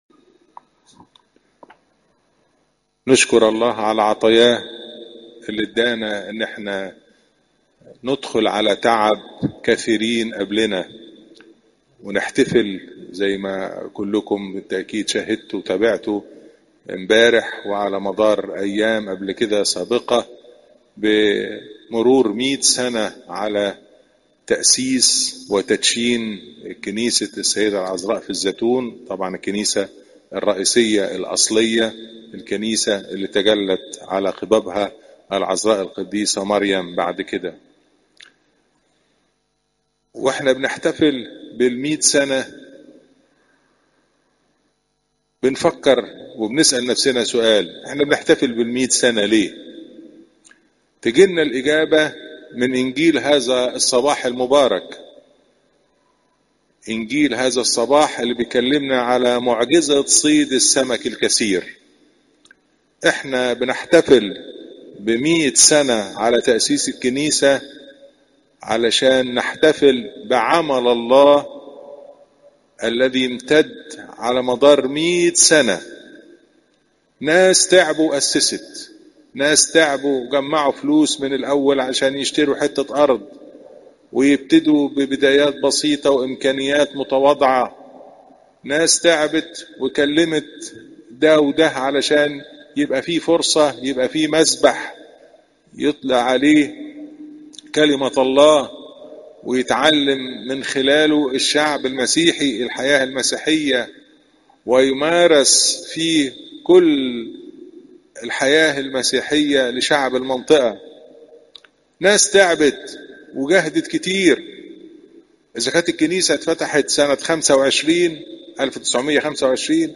عظات قداسات الكنيسة (لو 5 : 1 - 11)